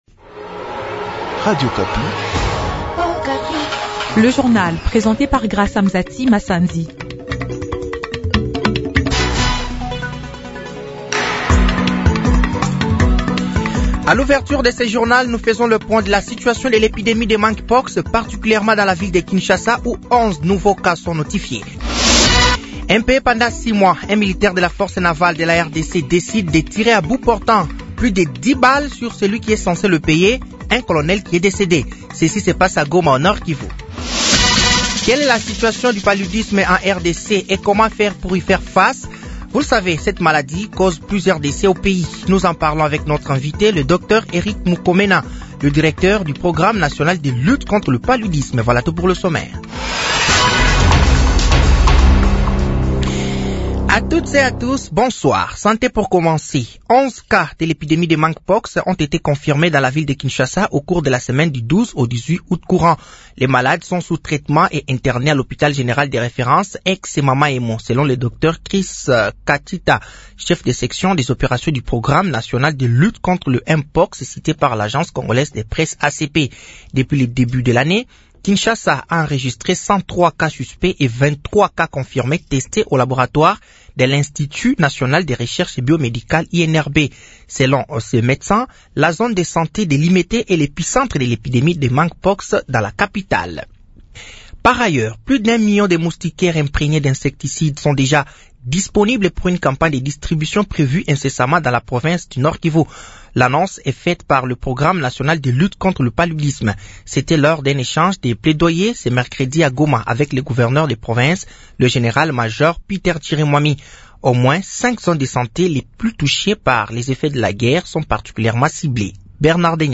Journal français de 18h de ce jeudi 22 août 2024